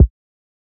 MB Kick (11).wav